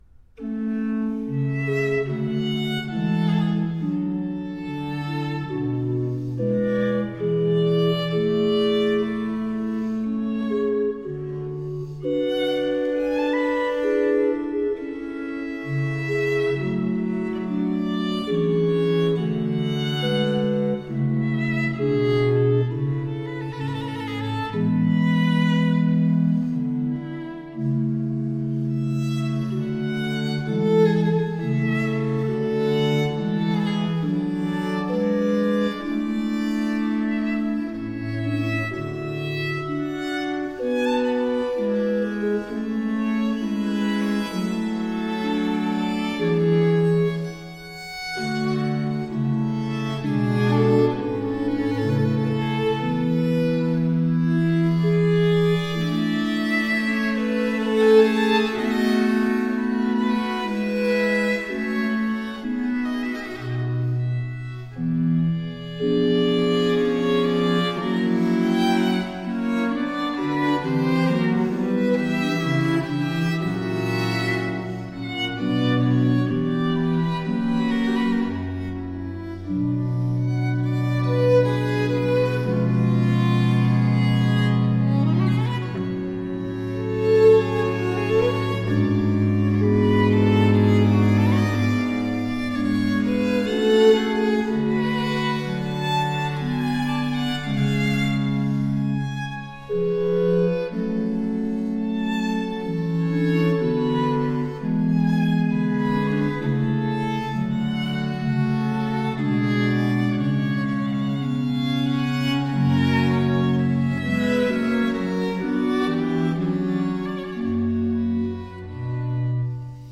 trio sonata in g minor
arioso